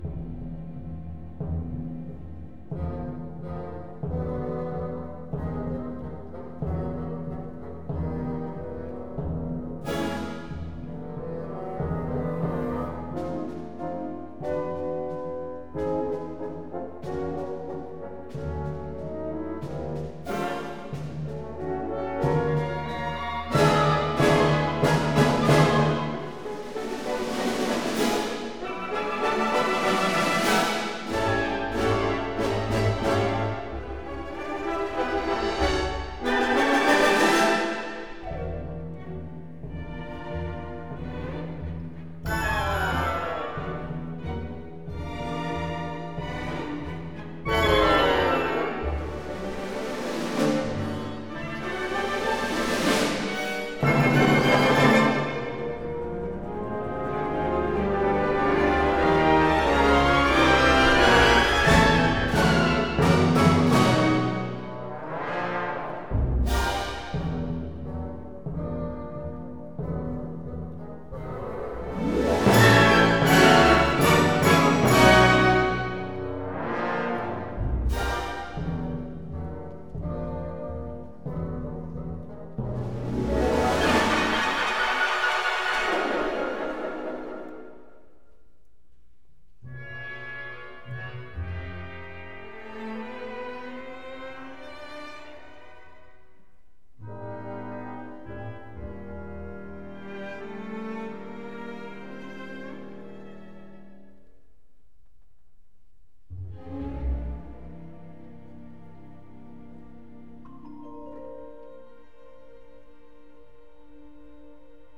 by Montreal Symphony Orchestra and Chorus; Charles Dutoit | Ravel: Daphnis et Chloé